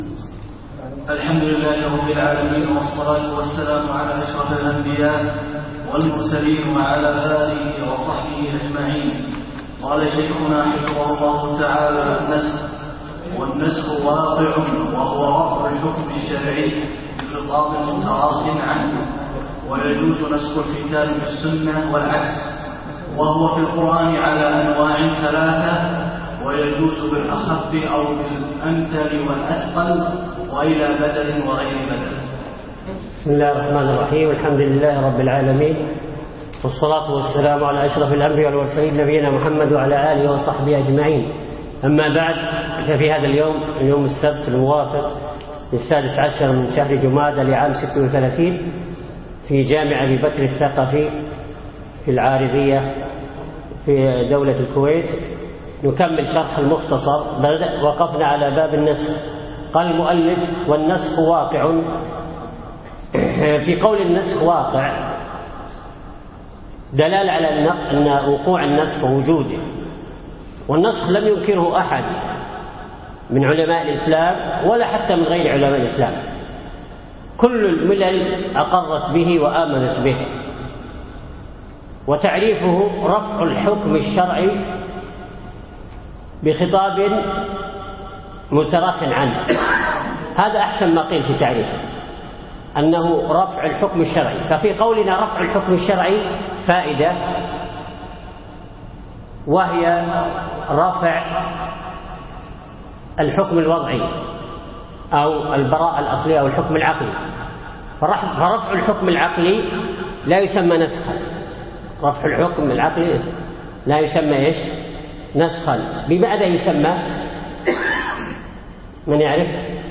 أقيم الدرس بعد عصر السبت 7 3 2015 في مسجد أبي بكرة الثقفي منطقة العارضية